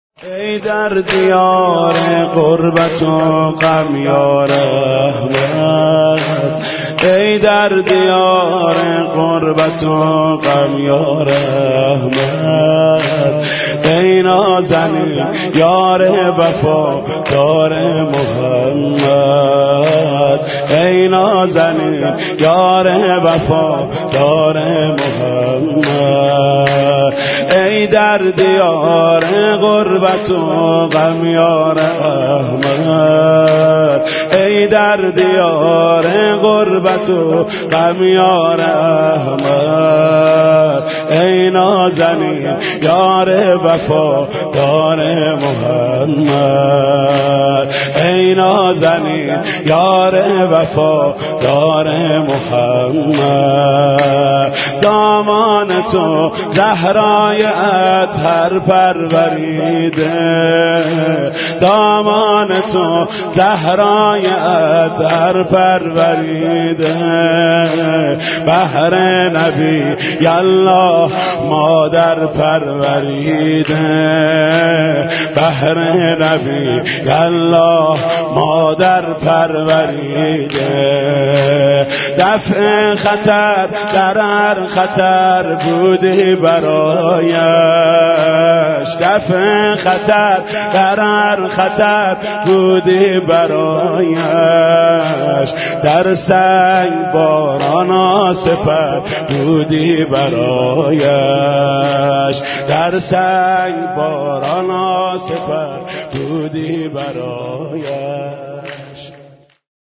کلمات کليدي: خدیجه، صوت، مداحی، وفات